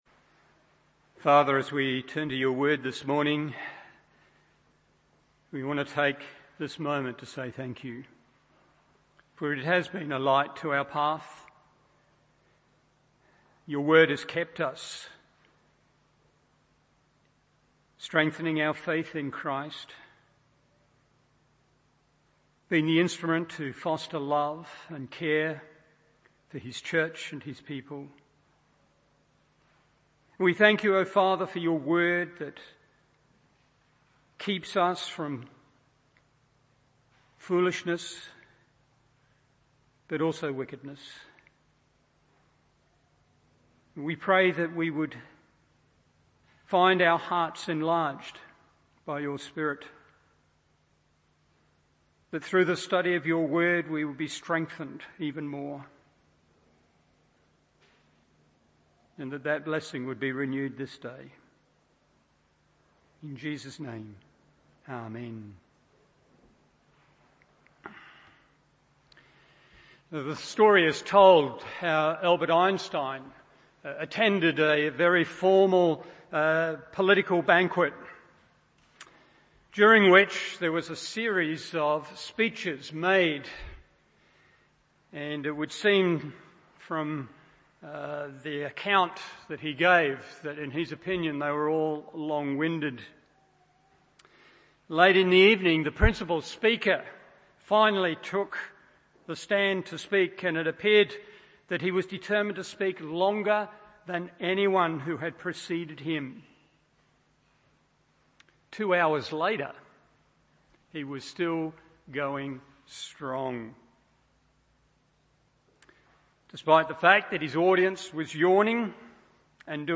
Morning Service Acts 1:10-11 1.